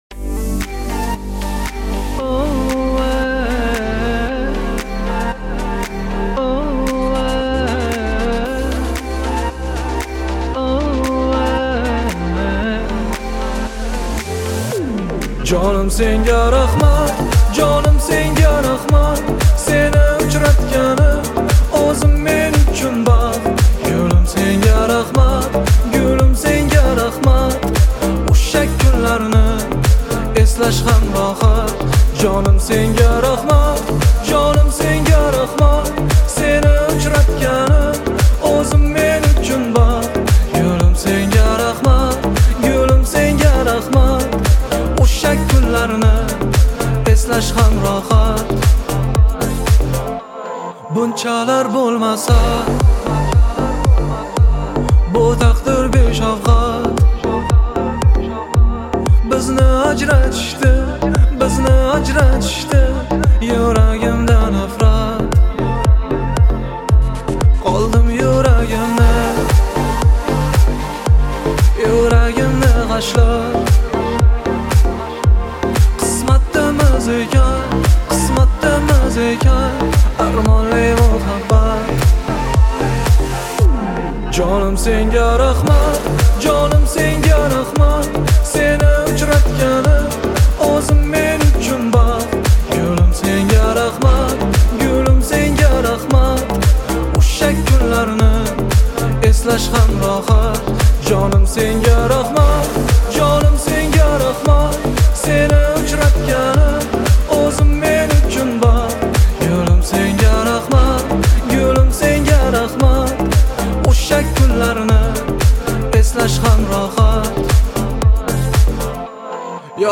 это яркий пример узбекской поп-музыки